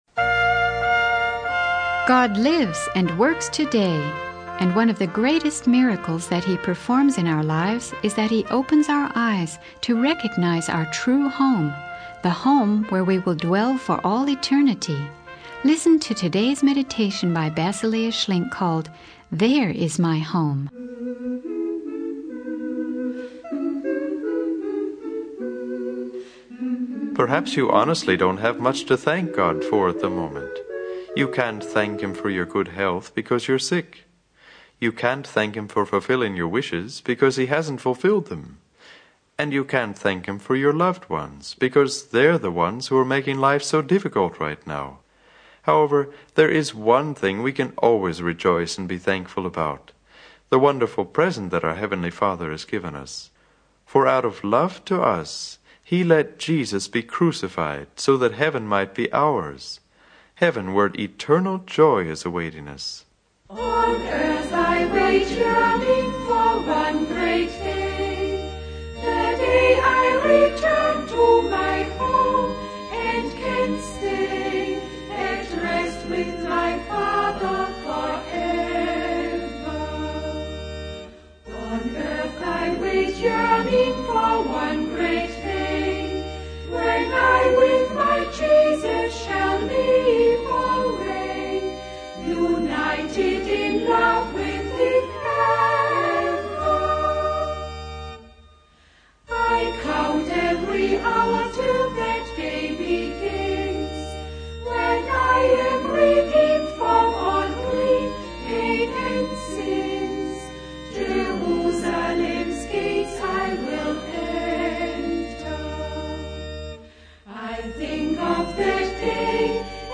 Basilea Schlink's sermon highlights the importance of recognizing our eternal home in heaven and the joy found in God's promises despite life's challenges.